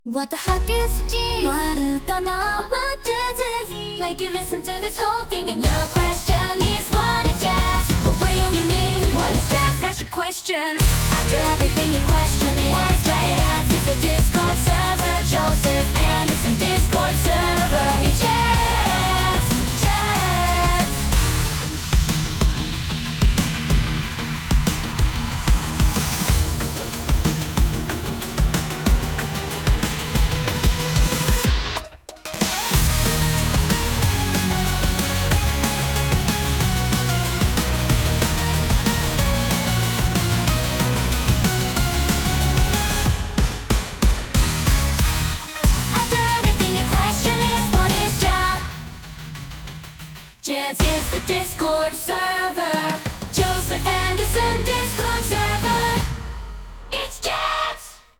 anisong